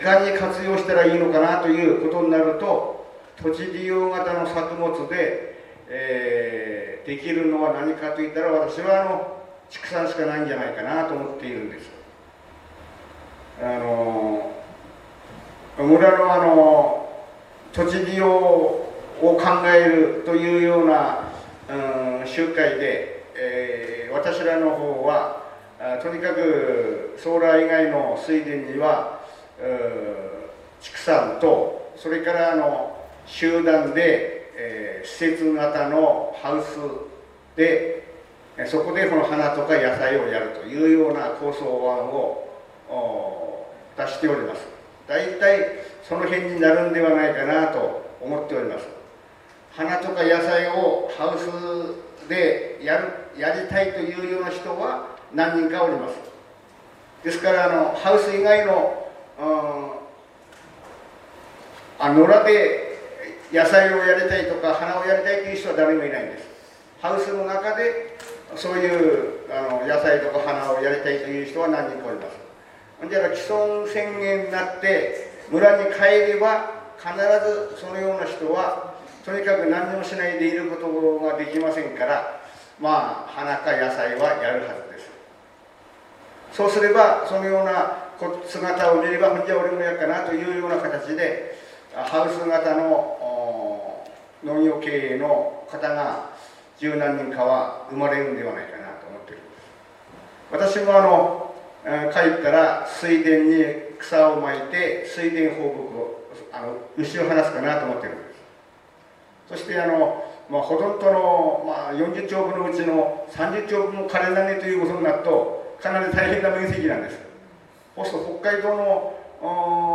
10月14日東京大学弥生講堂アネックスにて、東京で9回目となる報告会を開催しました。平日の夕方でしたが、70名あまりの方にご参加いただきました。